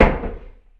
vaultThud.ogg